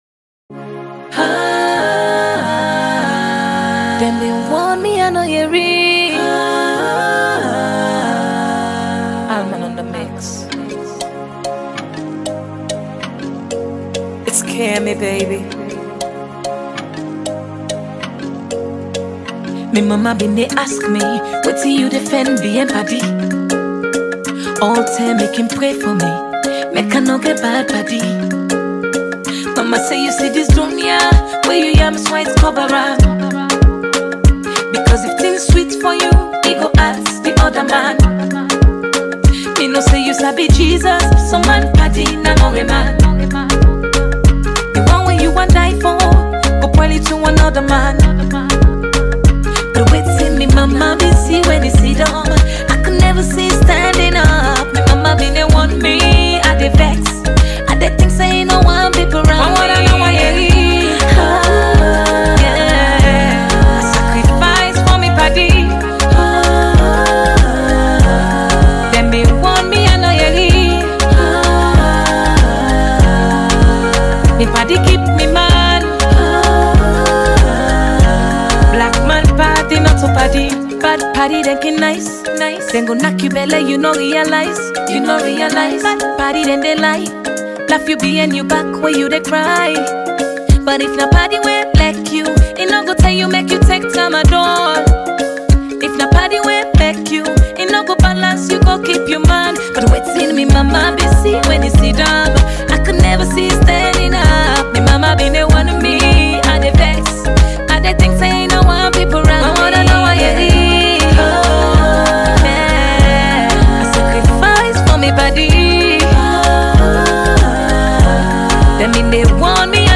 Sierra Leonean sensational singer